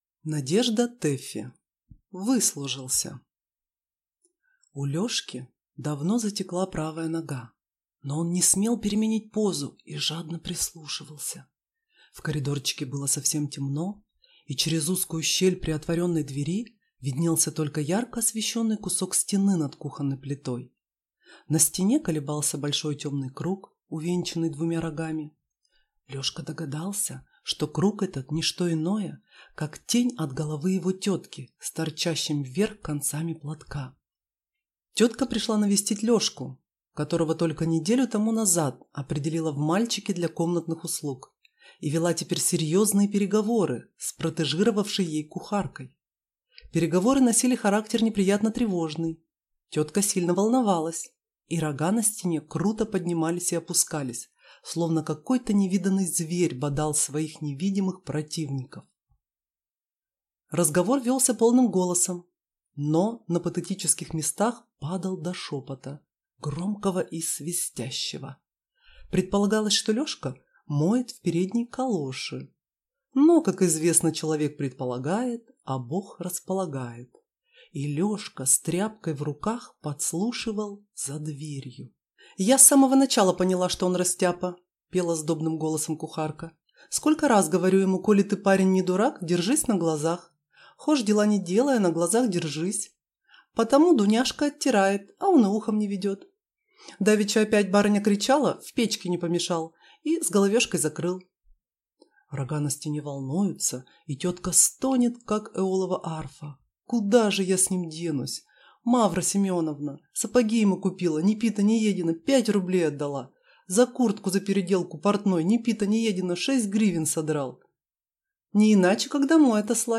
Аудиокнига Выслужился | Библиотека аудиокниг
Прослушать и бесплатно скачать фрагмент аудиокниги